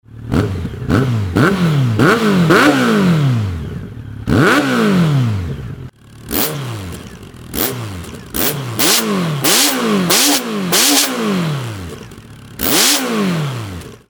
空ふかしの排気音（後半にバッフル付きに変わります）
通過する時にバッフルならではの音が発生していますが